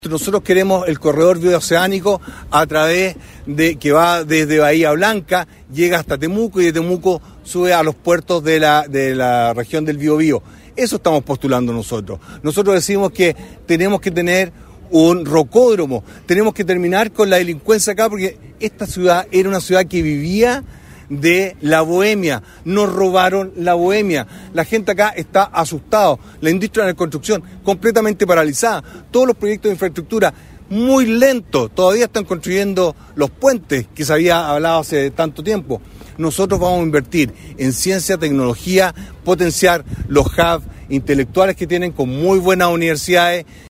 Desde la Plaza Independencia, en el centro de Concepción, y rodeado por alrededor de un centenar de adherentes, el postulante del Partido de la Gente (PDG) presentó algunas de sus propuestas, entre las que figura la baja de sueldos en la administración del estado, la devolución del IVA en los medicamentos y el término de las devoluciones de gasto electoral.